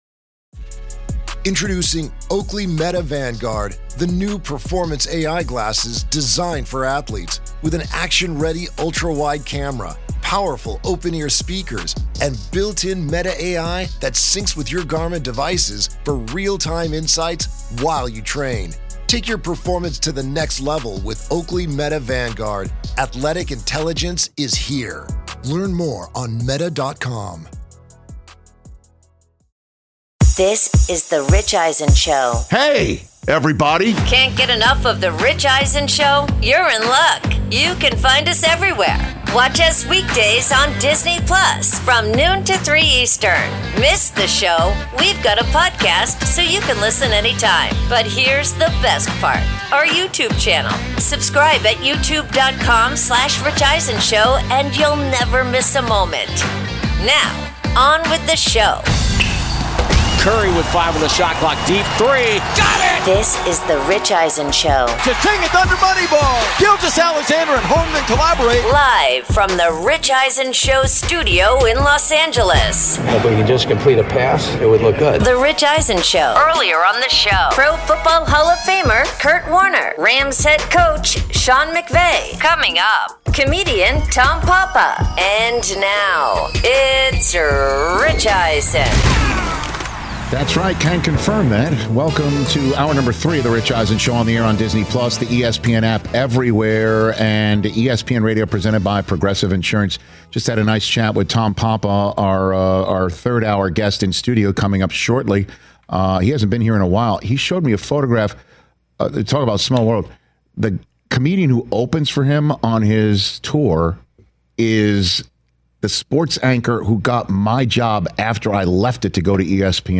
Hour 3: Rams Head Coach Sean McVay, plus Comedian Tom Papa In-Studio
Comedian Tom Papa joins Rich in-studio where he discusses being a long-suffering New York Giants fan, his passion for baking bread, his upcoming comedy tour, and reveals that he has never had a case of protector for his mobile phone.